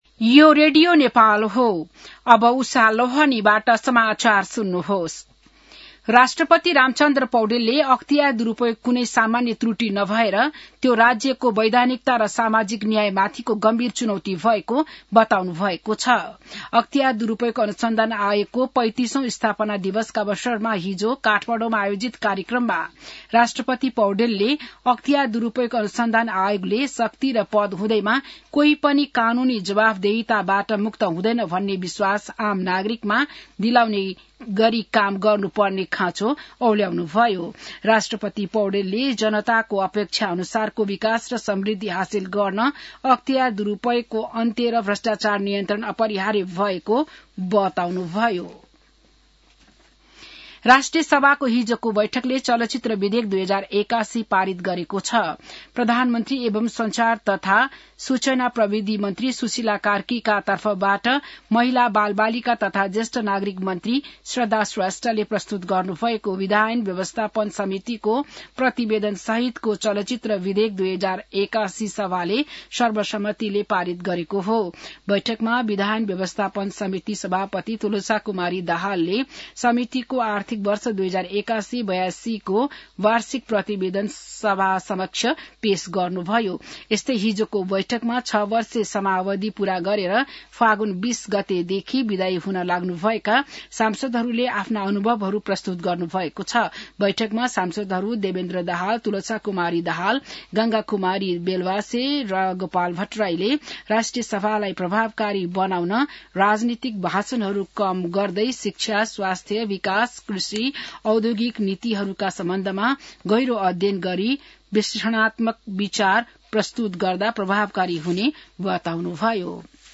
बिहान १० बजेको नेपाली समाचार : २९ माघ , २०८२